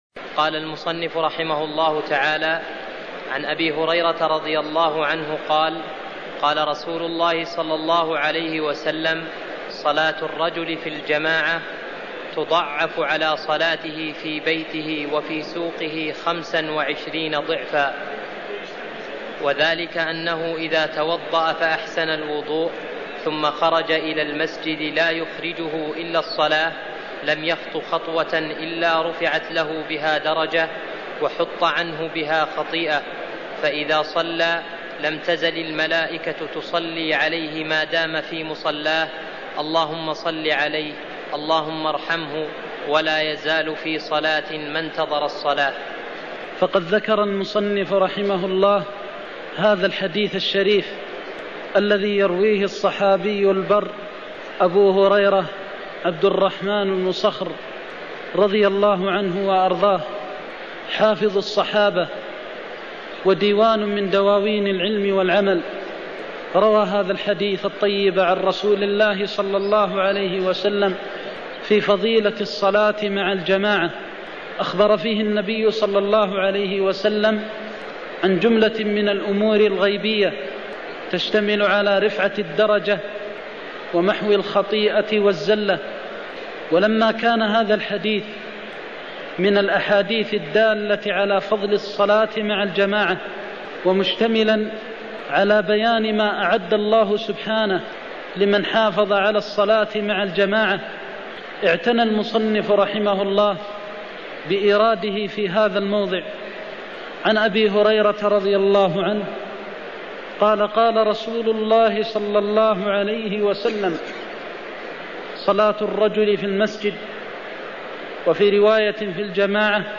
المكان: المسجد النبوي الشيخ: فضيلة الشيخ د. محمد بن محمد المختار فضيلة الشيخ د. محمد بن محمد المختار صلاة الرجل في الجماعة تضعف على صلاته في بيته (55)